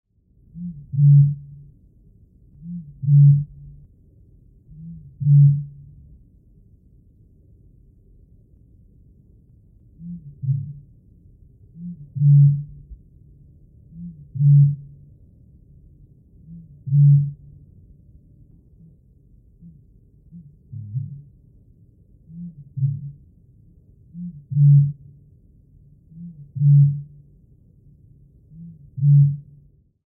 Nicht alle Vögel singen melodiöse Gesänge. Kennst du zum Beispiel die Rohrdommel?
1655_Rohrdommel_Rufe_short.mp3